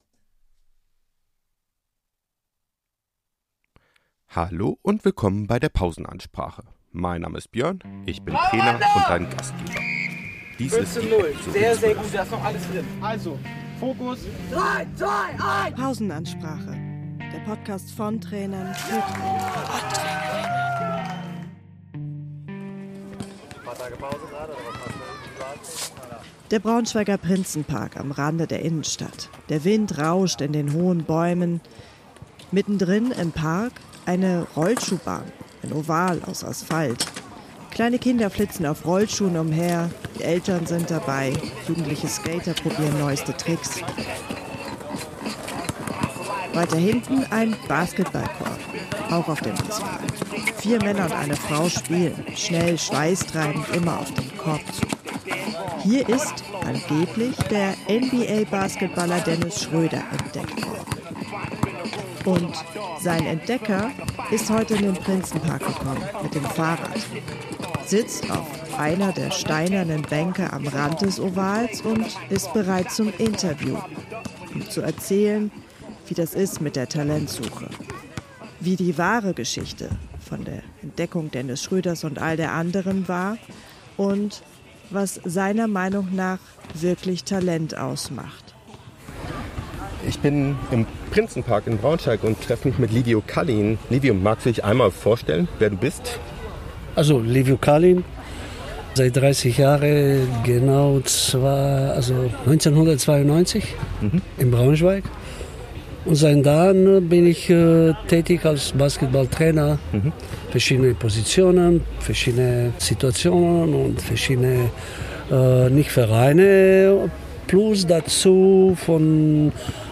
Hier im Interview!